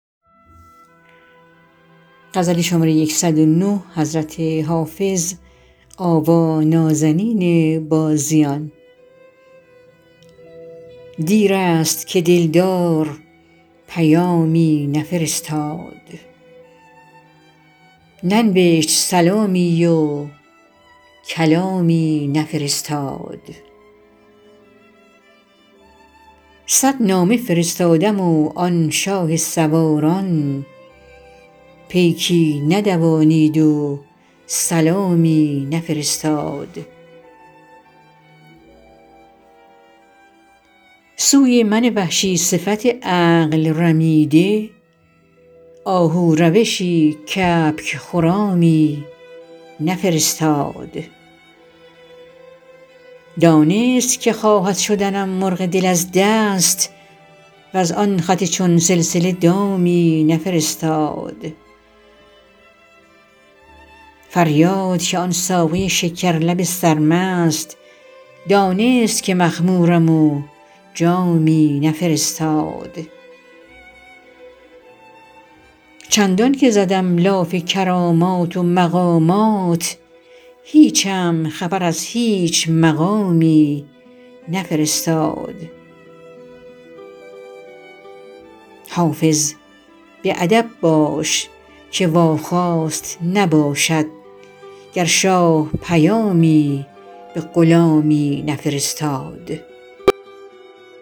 حافظ غزلیات غزل شمارهٔ ۱۰۹ به خوانش